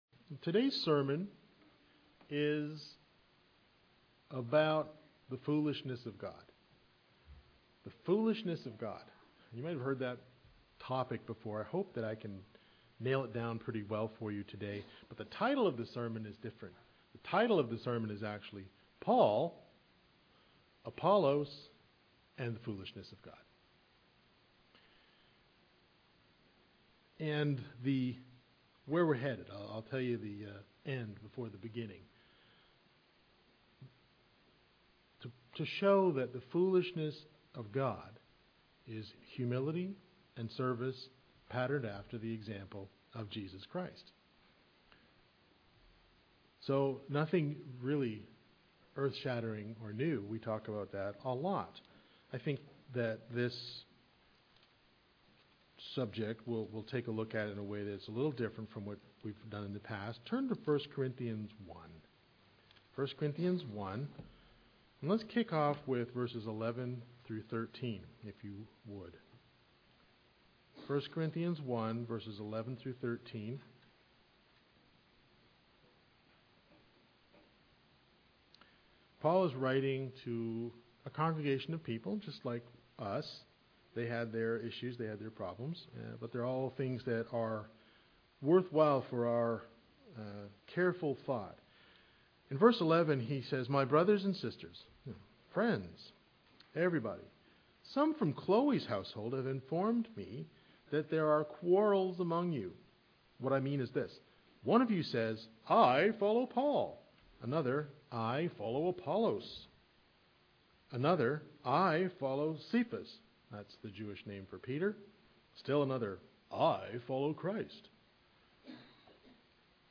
Sermons
Given in Greensboro, NC